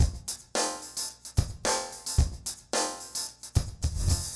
RemixedDrums_110BPM_29.wav